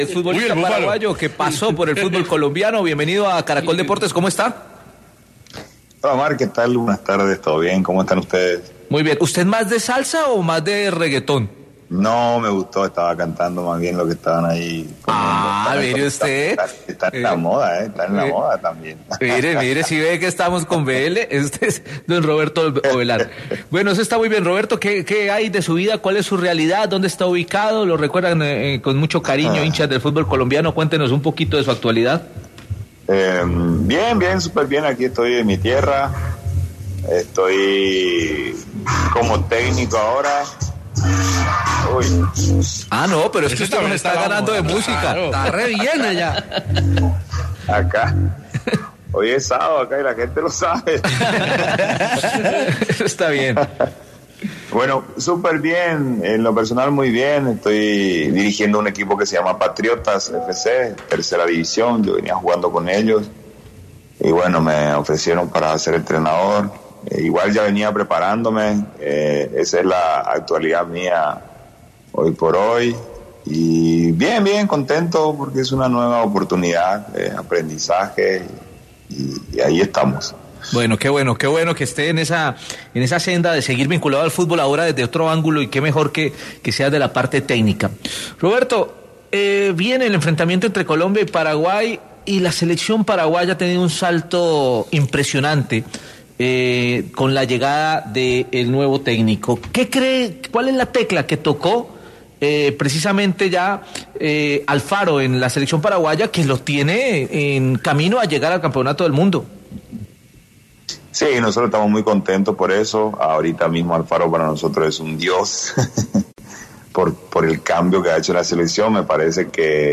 Declaraciones de Roberto Ovelar